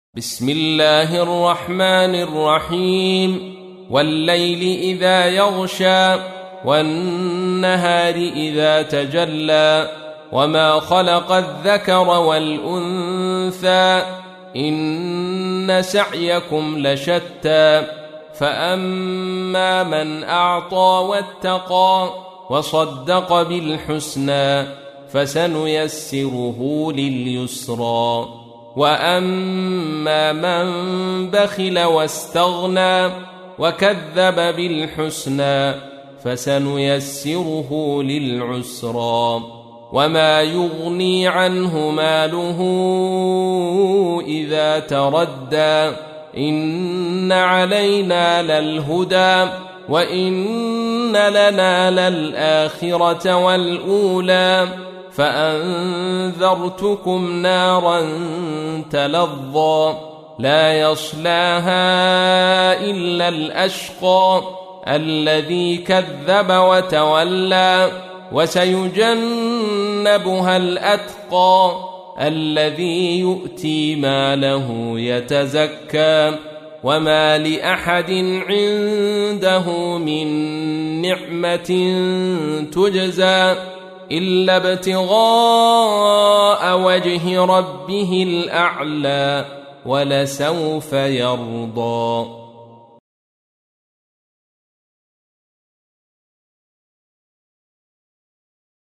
تحميل : 92. سورة الليل / القارئ عبد الرشيد صوفي / القرآن الكريم / موقع يا حسين